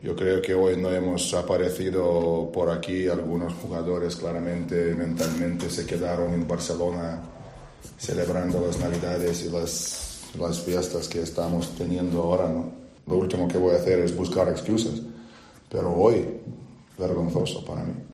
El entrenador del Barça, Sarunas Jasikevicius, afirmó tras la derrota de su equipo ante el Bitci Baskonia que el partido de lo suyos fue "vergonzoso".